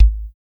SWIFT KICK.wav